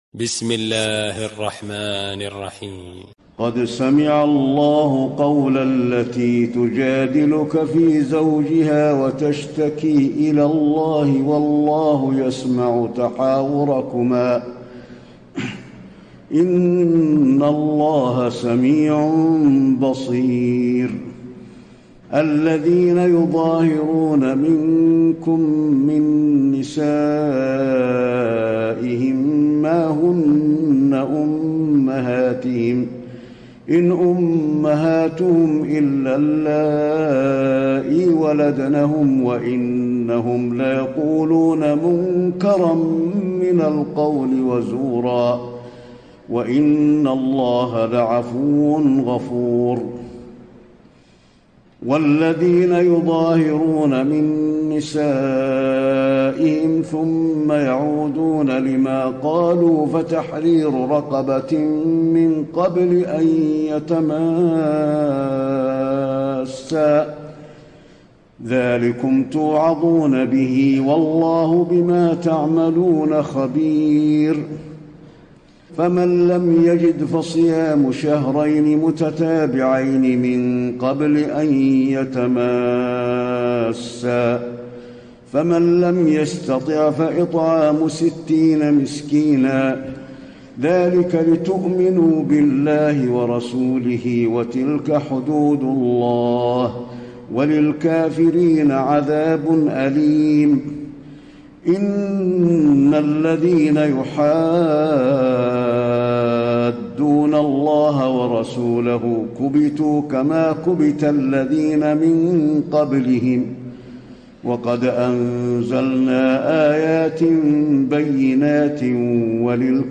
تراويح ليلة 27 رمضان 1434هـ من سورة المجادلة الى الصف Taraweeh 27 st night Ramadan 1434H from Surah Al-Mujaadila to As-Saff > تراويح الحرم النبوي عام 1434 🕌 > التراويح - تلاوات الحرمين